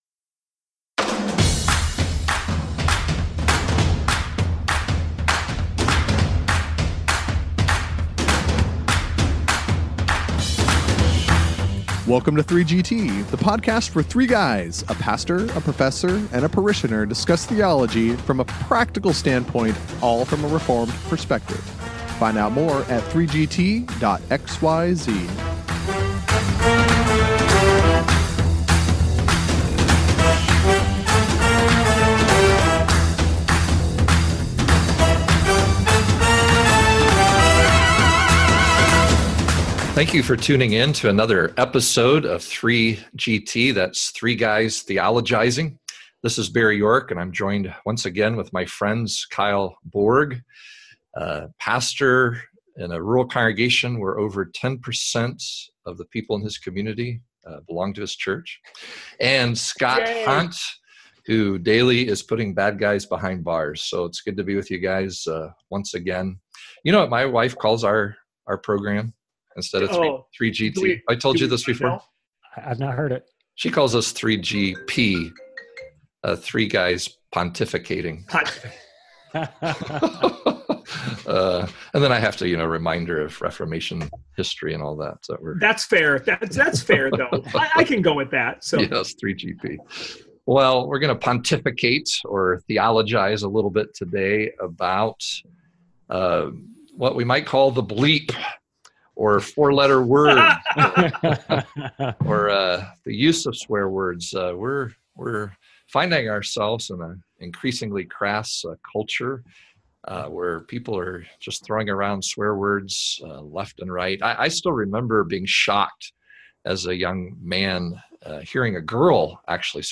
Tune into this bleep-free episode of 3GT!